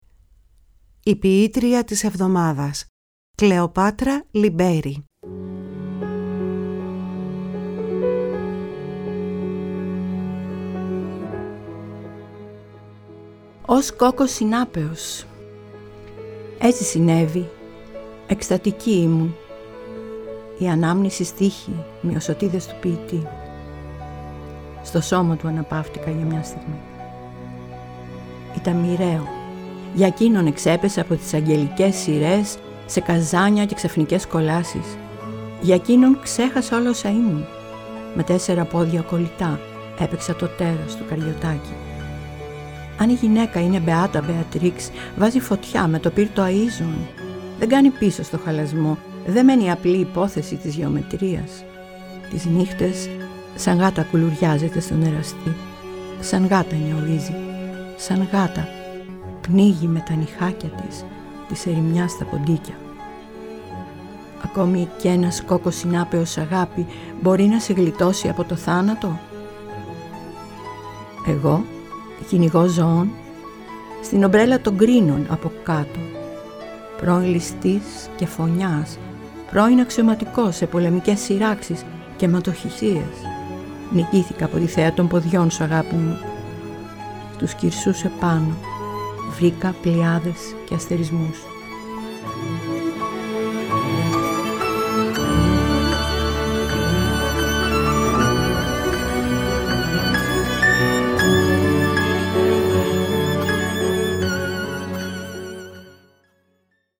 Επτά ποιήματα κάθε εβδομάδα, ένα ελληνικό ποίημα κάθε ημέρα, ταξιδεύει μέσα από τις συχνότητες της ΦΩΝΗΣ ΤΗΣ ΕΛΛΑΔΑΣ, στα πέρατα της γης, όπου υπάρχουν ελληνικές φωνές.